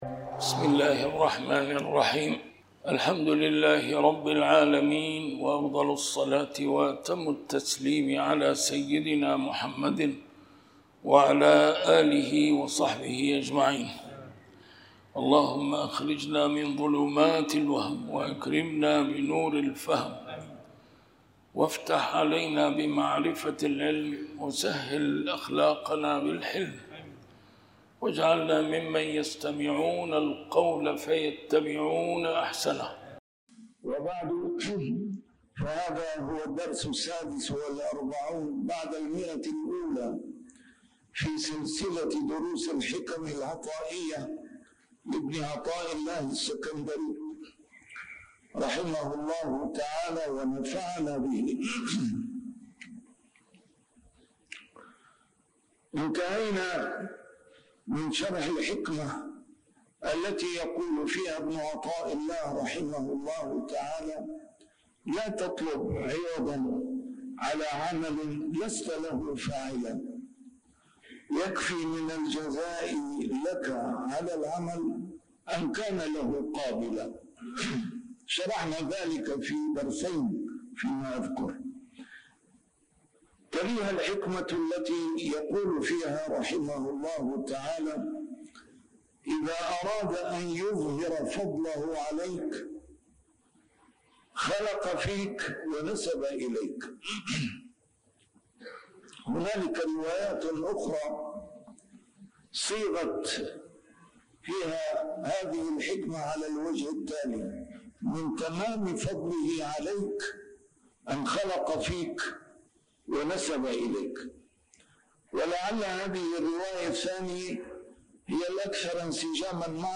A MARTYR SCHOLAR: IMAM MUHAMMAD SAEED RAMADAN AL-BOUTI - الدروس العلمية - شرح الحكم العطائية - الدرس رقم 146 شرح الحكمة 123